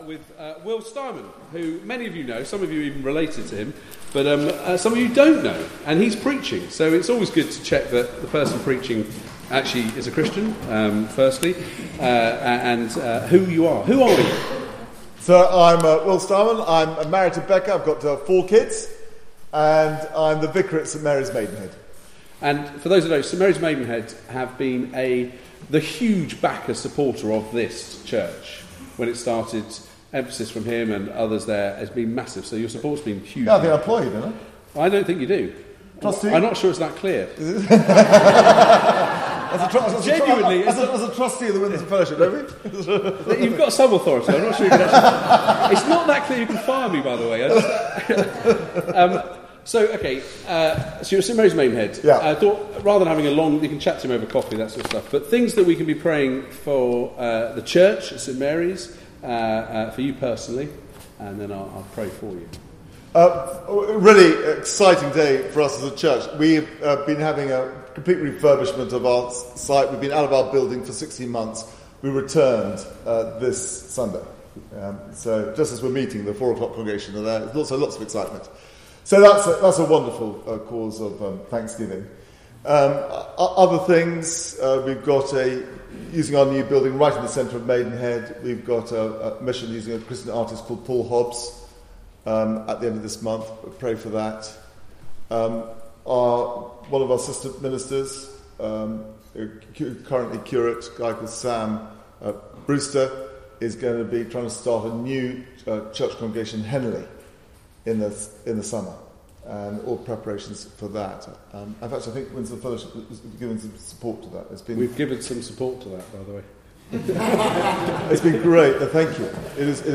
This includes the interview and reading.
Service Type: Weekly Service at 4pm